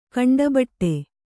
♪ kaṇḍabaṭṭe